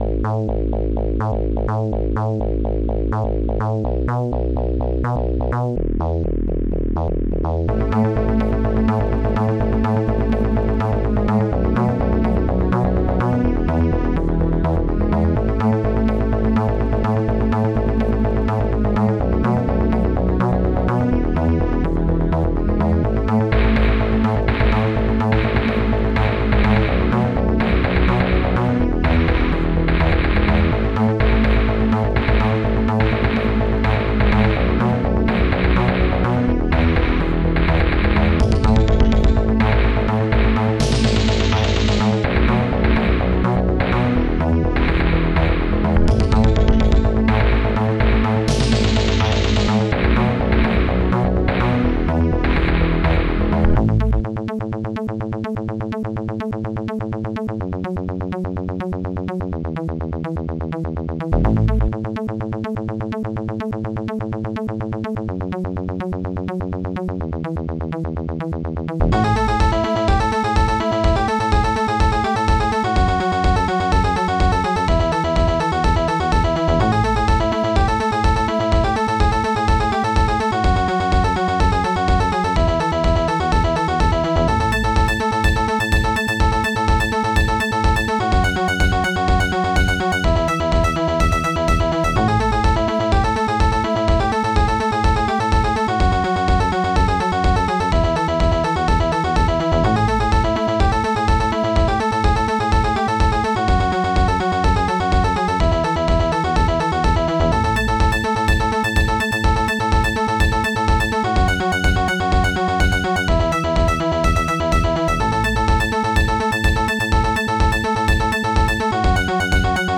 Digital Symphony Module
Instruments monobass digdug strings2 popsnare2 strings7 bassdrum3 shamus mechanic1 hihat1